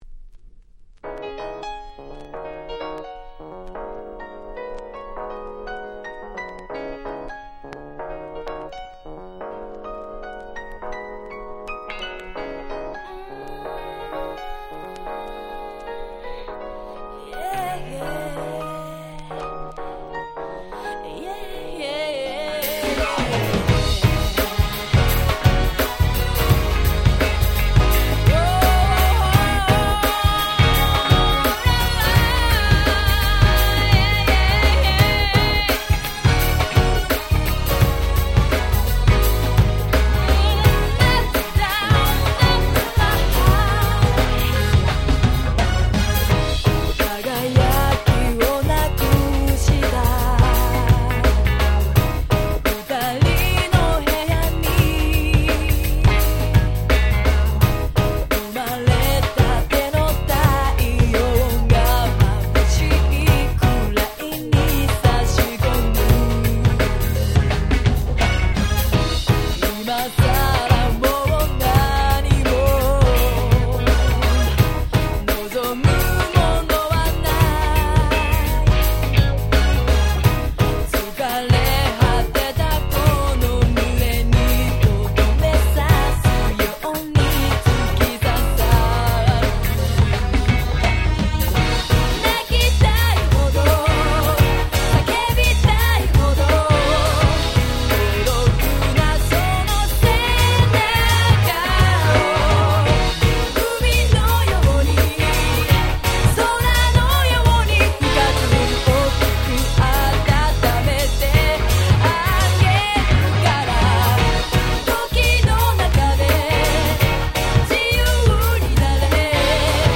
99' Nice Japanese R&B !!